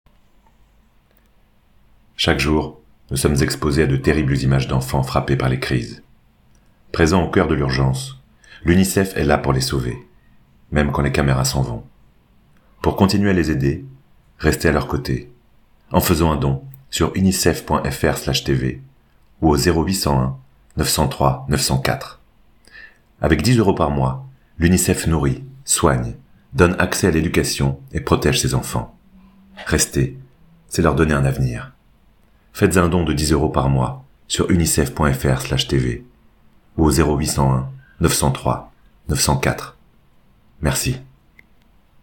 - Basse